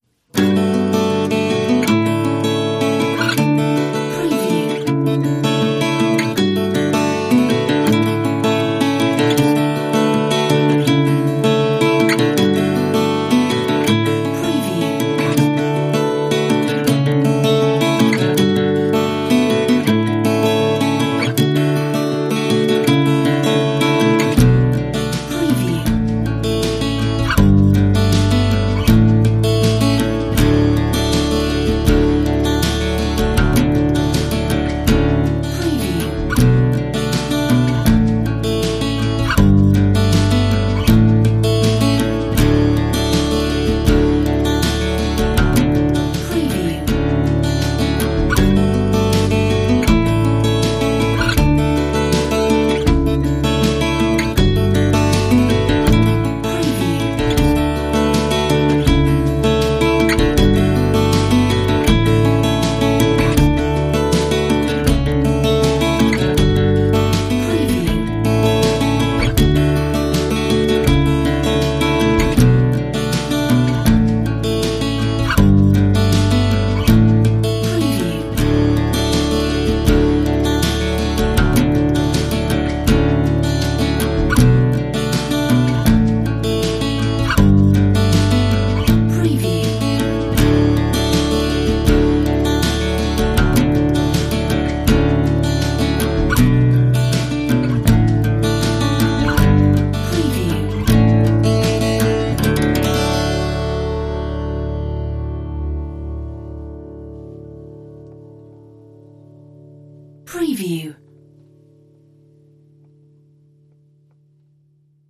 Delicate acoustic track